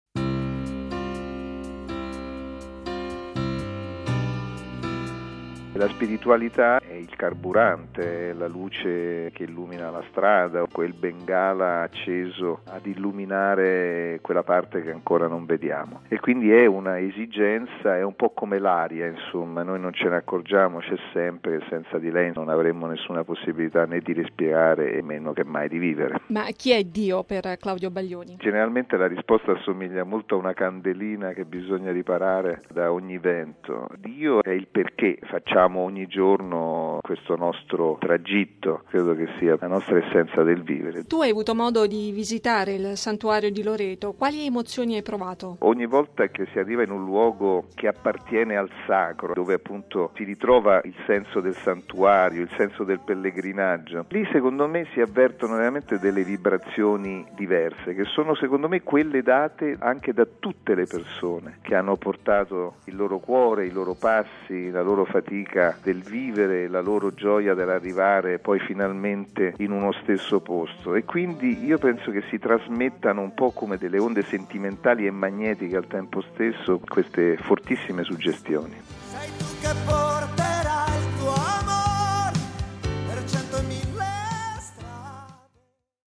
Oltre alla musica, naturalmente, la spiritualità sarà la grande protagonista dell’Agorà di Loreto. Ascoltiamo un altro dei cantautori presenti la sera del primo settembre sulla spianata di Montorso: Claudio Baglioni: RealAudio